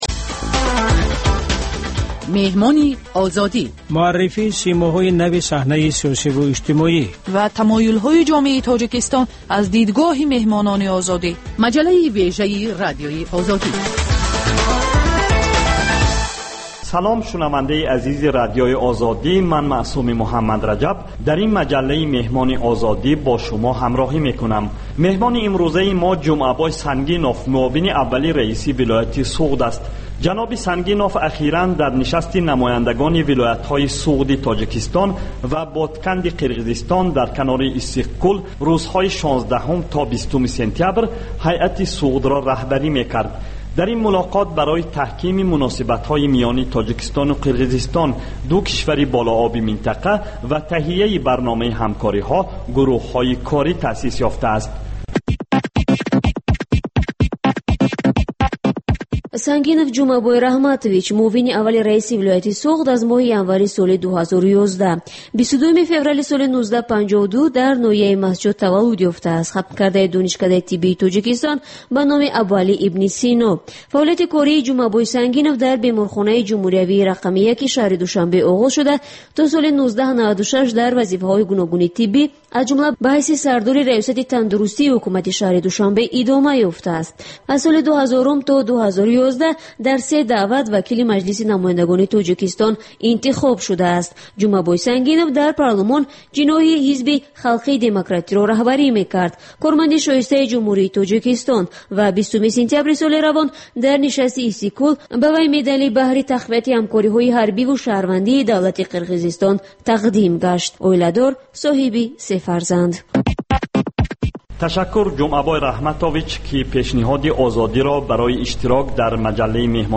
Сӯҳбати ошкоро бо чеҳраҳои саршинос ва мӯътабари Тоҷикистон бо пурсишҳои сангин ва бидуни марз.